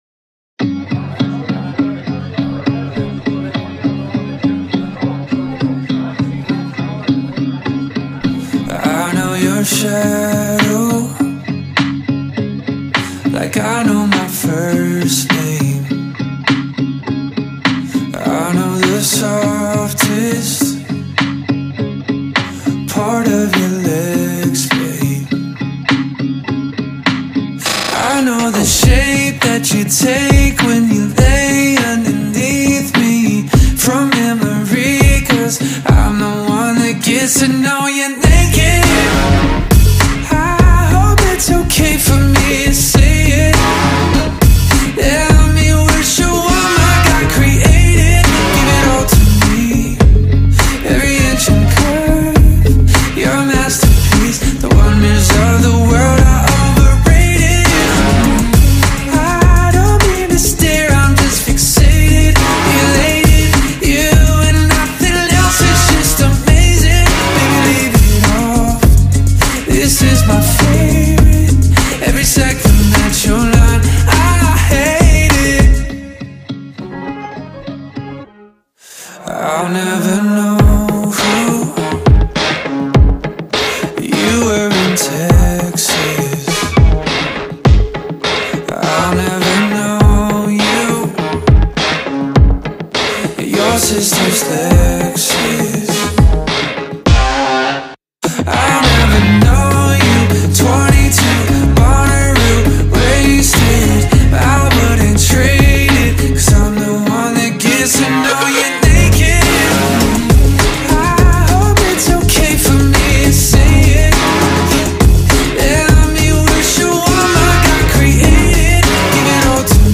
поп рок
альтернативного рок-дуэта